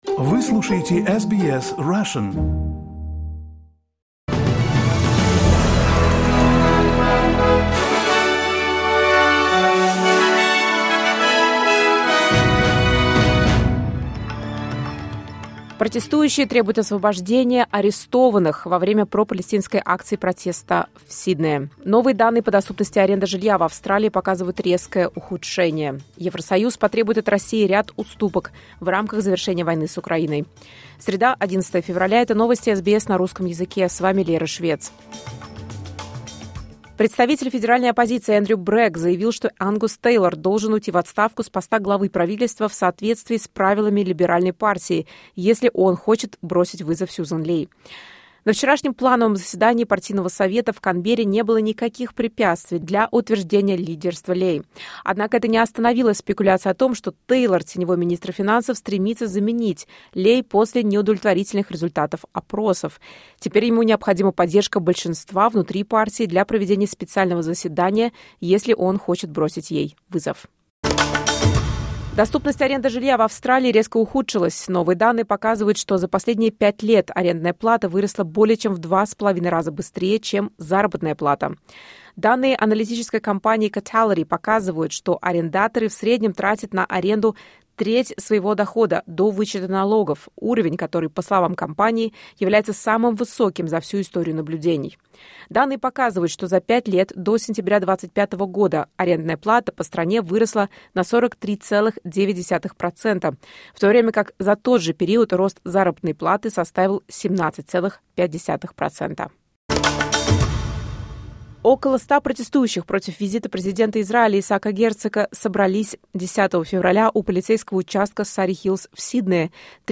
Новости SBS на русском языке — 11.02.2026